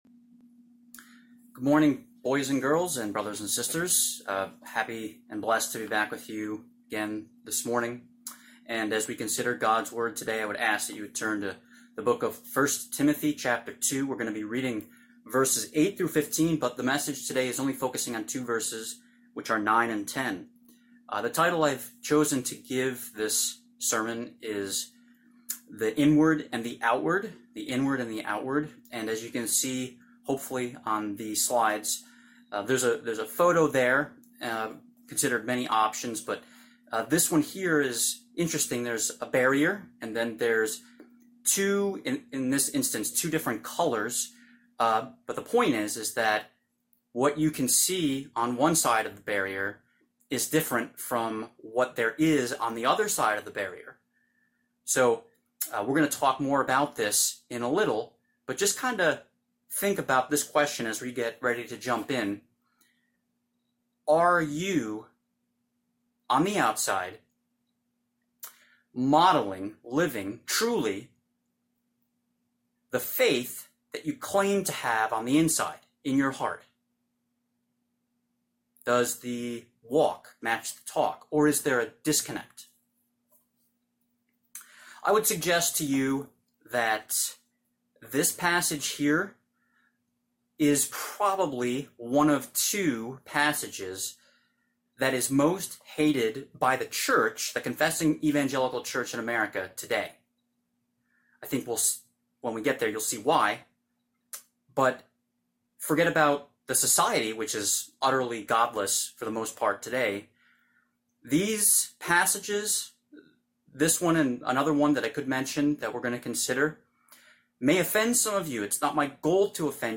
Sermon – Chinese Christian Church of Greater Albany